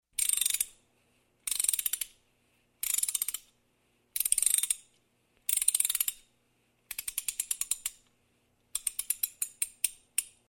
Звук завода куклы